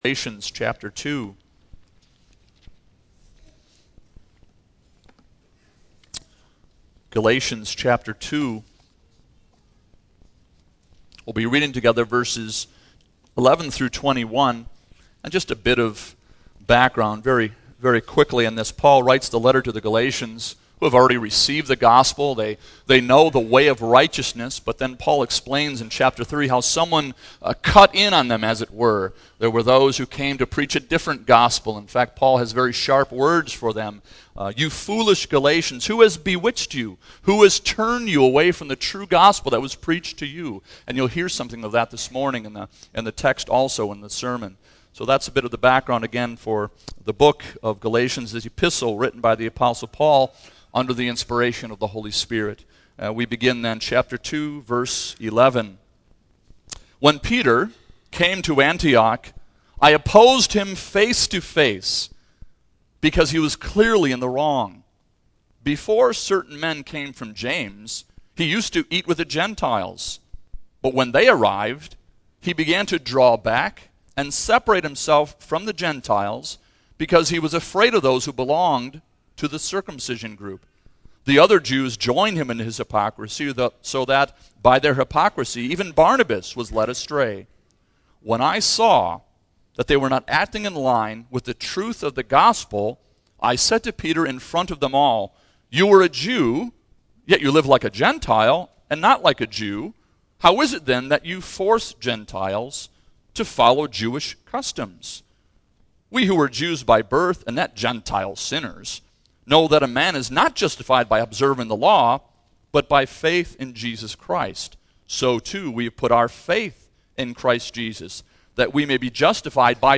Single Sermons Passage: Galatians 2:11-21 %todo_render% « A Comforting Message